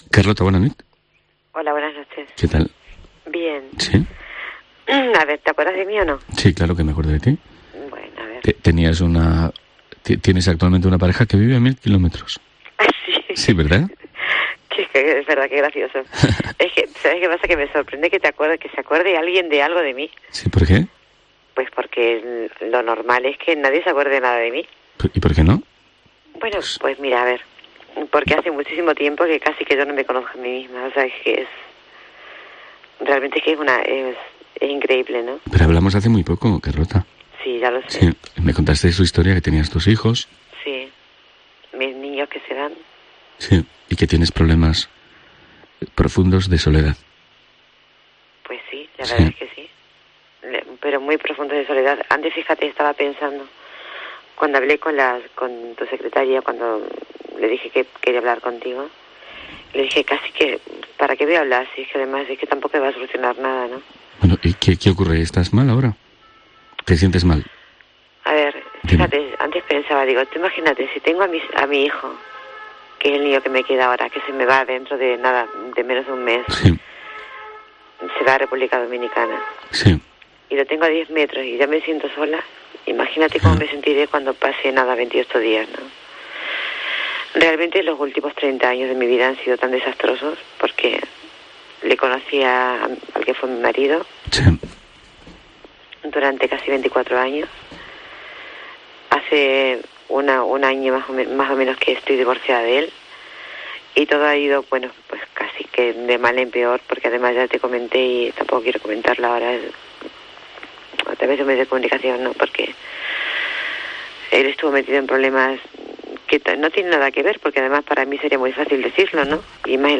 Radio de madrugada